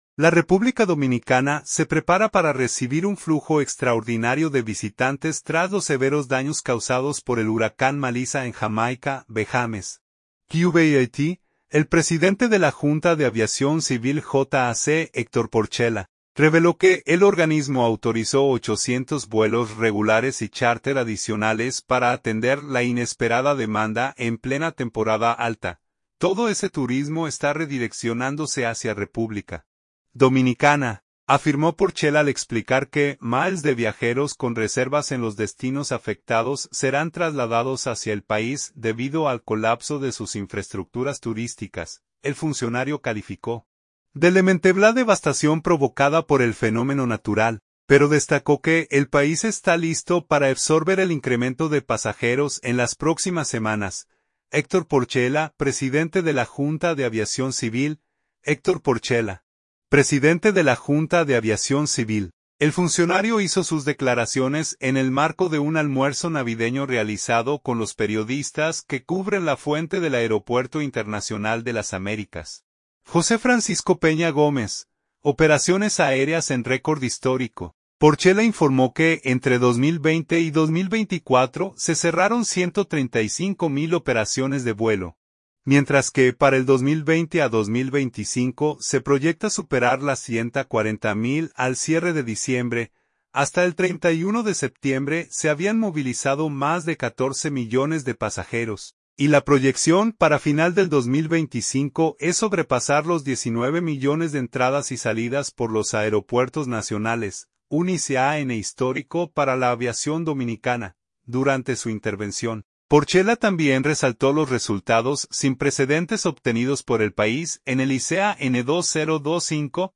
El funcionario hizo sus declaraciones en el marco de un almuerzo navideño realizado con los periodistas que cubren la fuente del aeropuerto internacional de Las Américas, José Francisco Peña Gómez.